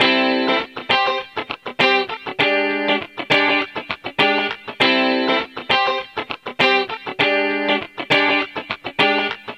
Sons et loops gratuits de guitares rythmiques 100bpm
Guitare rythmique 42